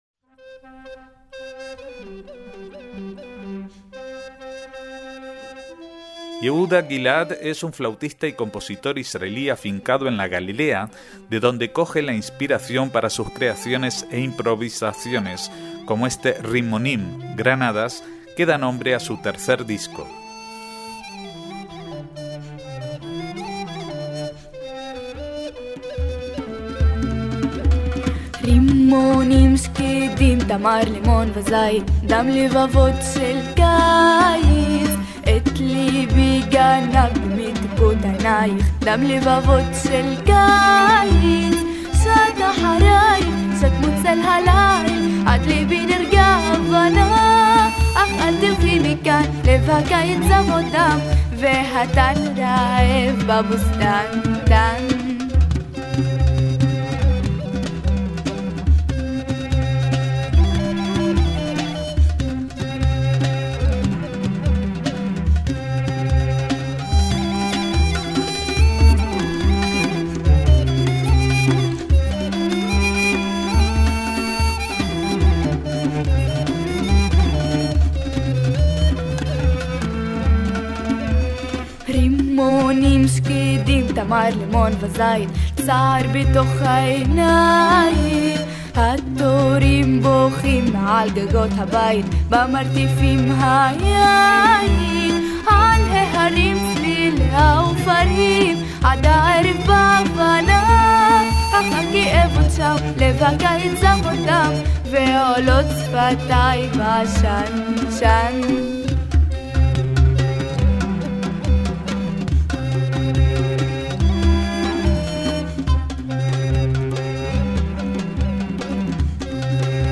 flautista